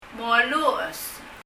» write 書く moluches [məlu ? əs] 英） write 日） 書く Leave a Reply 返信をキャンセルする。